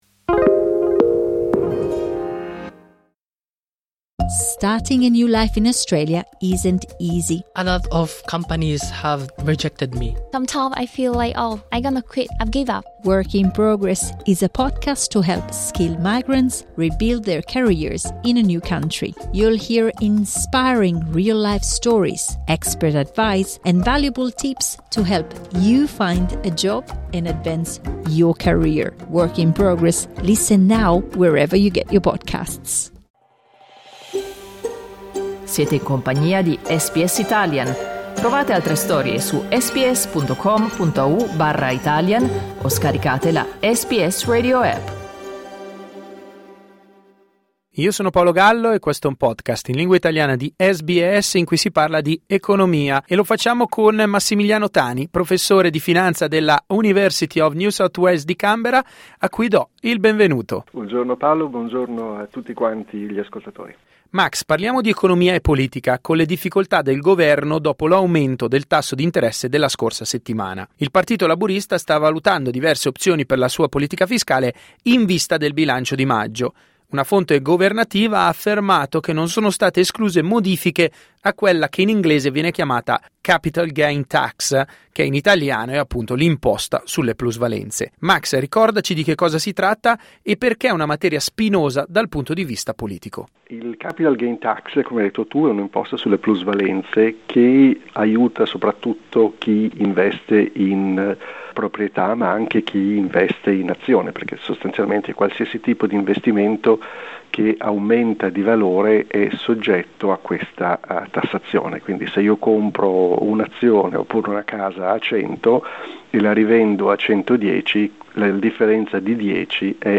La Reserve Bank of Australia ha previsto la peggiore crescita economica a medio termine mai registrata. Il punto con il professore di Finanza